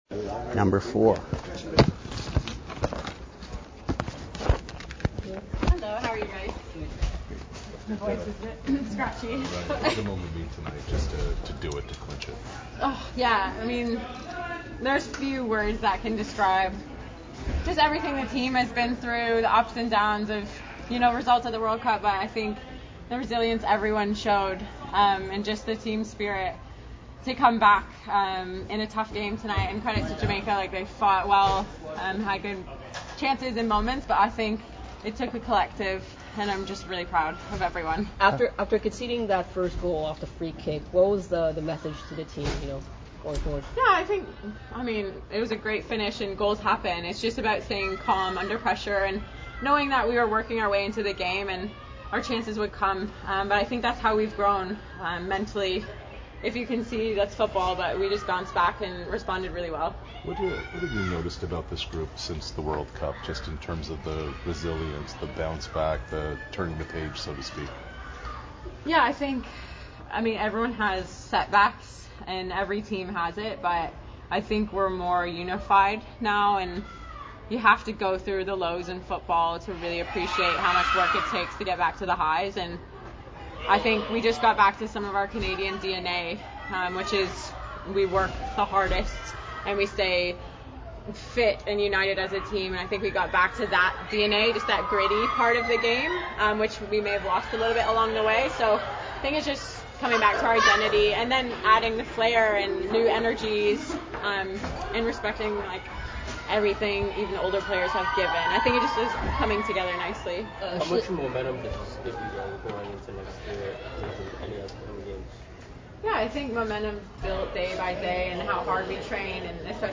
Details of the post-game press conference held Tuesday September 26, 2023 at BMO Field in Toronto
reached over with my phone to hear the questions and answers.
Canadian centre back Shelina Zadorsky